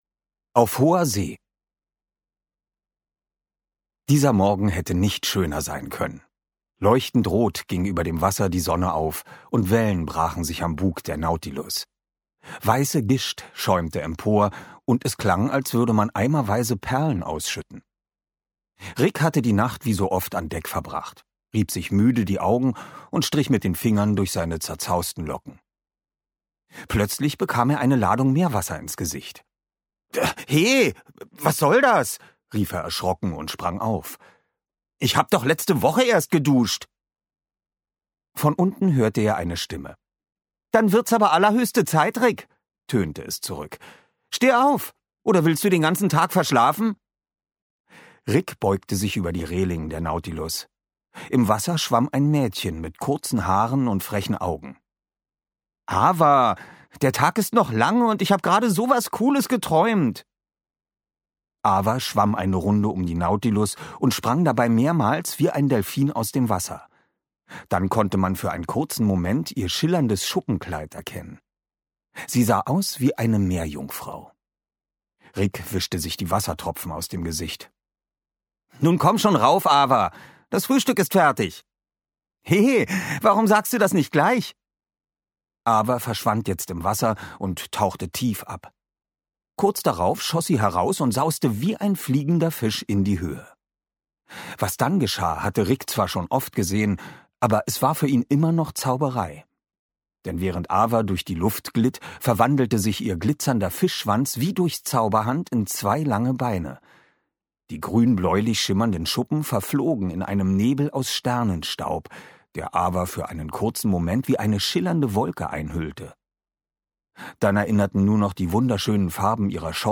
Rick Nautilus – Teil 1: SOS aus der Tiefe Ungekürzte Lesung mit Musik mit Oliver Rohrbeck
Oliver Rohrbeck (Sprecher)